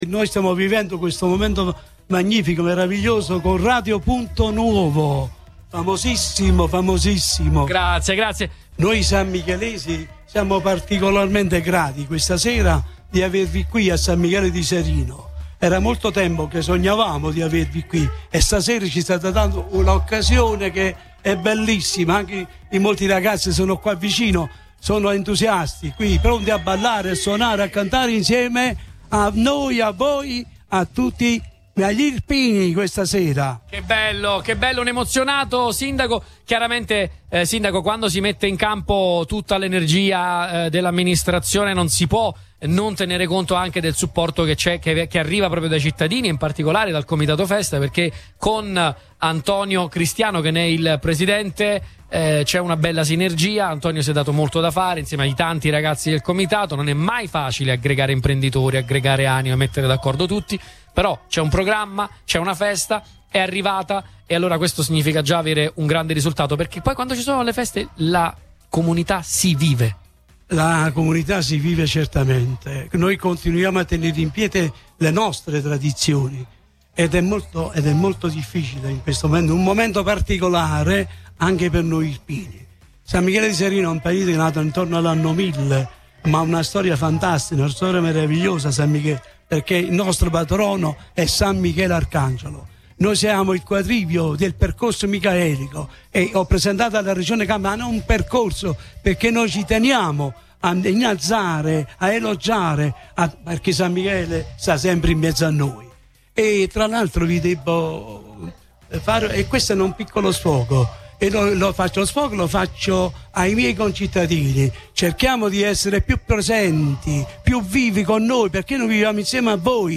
San Michele di Serino in festa: il sindaco Boccia emozionato ai microfoni di Radio Punto Nuovo
In diretta dalla festa patronale, il primo cittadino ringrazia il Comitato Festa, la comunità e rilancia l’invito: “Più presenza, più compattezza: così si tengono vive le nostre tradizioni”